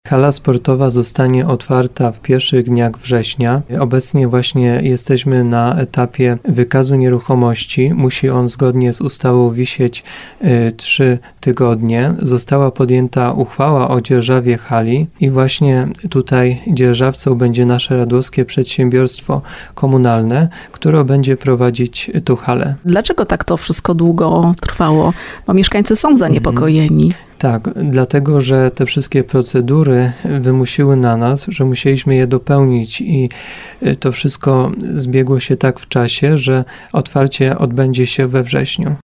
Burmistrz Radłowa Mateusz Borowiec w audycji Słowo za Słowo mówił o terminie otwarcia hali.